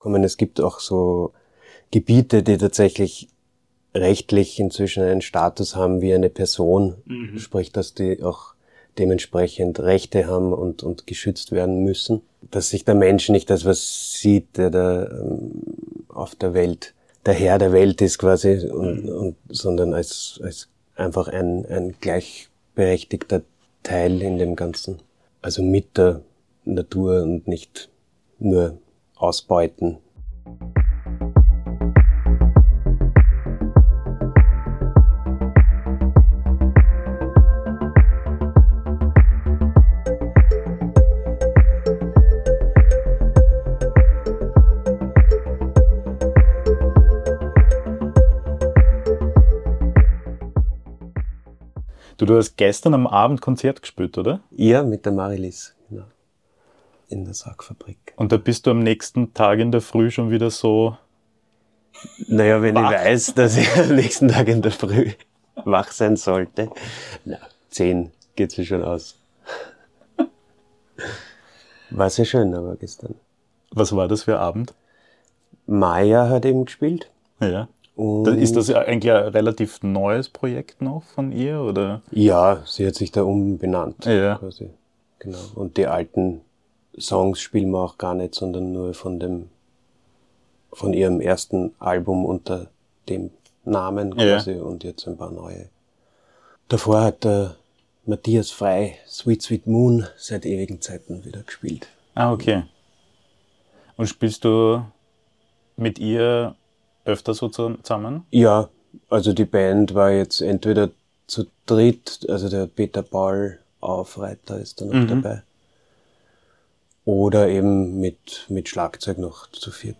Ein Gespräch über Musik als Spurensuche, über das Hören als Haltung und über die Kunst, Veränderung nicht nur abzubilden, sondern hörbar zu machen.